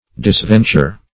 Disventure \Dis*ven"ture\